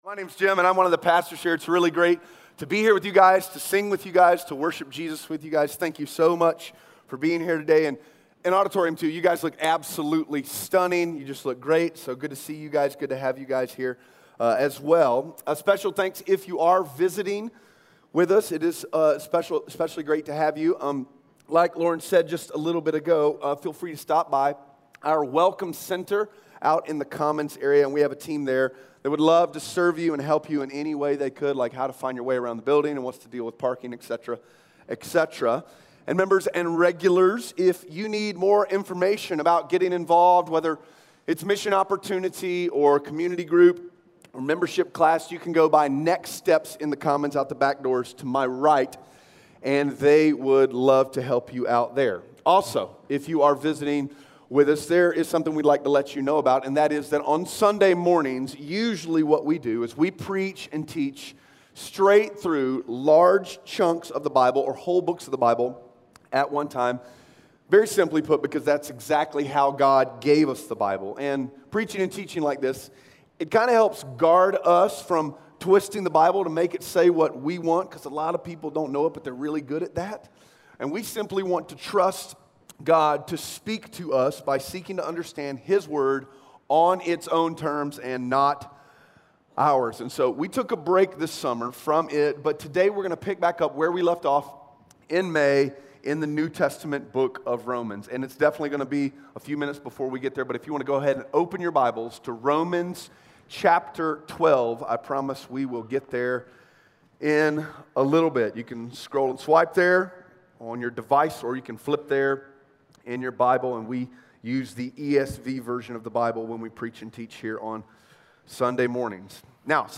Romans 12:1-2 Audio Sermon Notes (PDF) Ask a Question Isn’t it sad when Christians are exactly like everybody else?